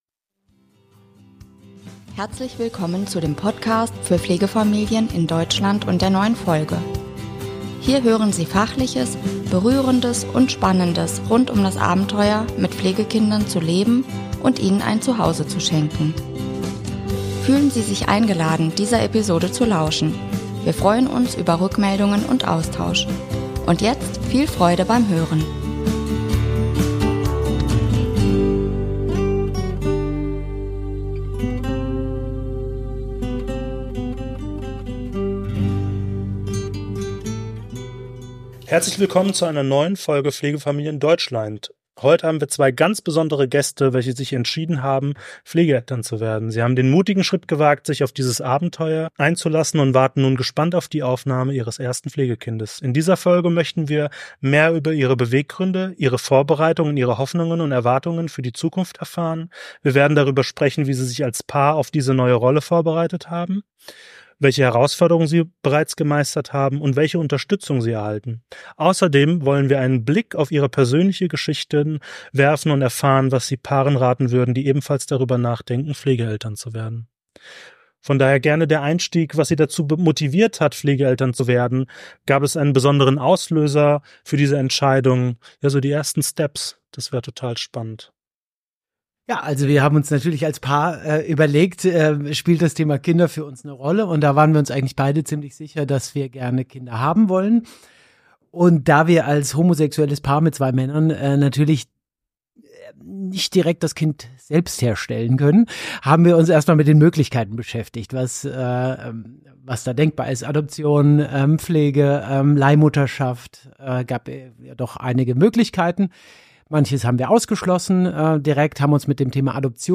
Heute sprechen wir mit einem gleichgeschlechtlichen Paar, das gerade das Anerkennungsverfahren als Pflegefamilie abgeschlossen hat und nun die Zeit des Wartens auf das Pflegekind beginnt. In dieser Folge möchten wir mehr über ihre Beweggründe, ihre Vorbereitung und ihre Hoffnungen und Erwartungen für die Zukunft erfahren. Wir werden darüber sprechen, wie sie sich als Paar auf diese neue Rolle vorbereitet haben, welche Herausforderungen sie bereits gemeistert haben und welche Unterstützung sie erhalten.